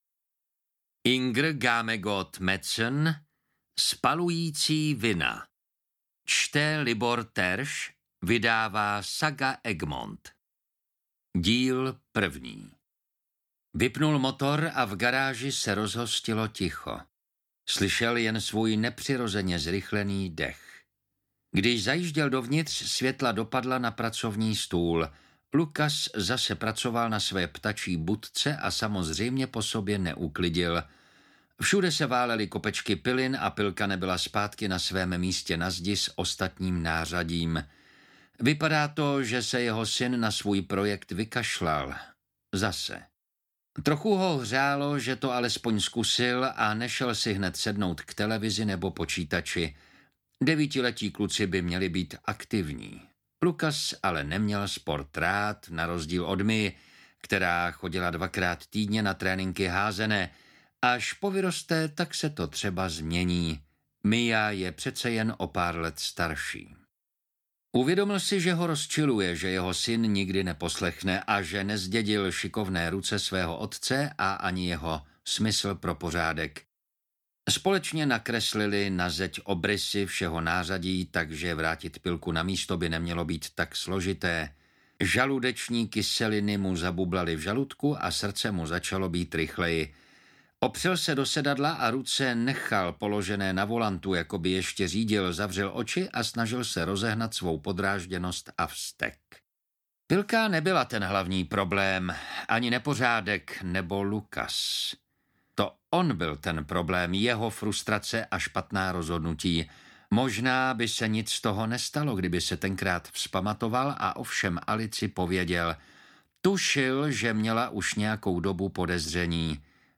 Spalující vina - Díl 1 audiokniha
Ukázka z knihy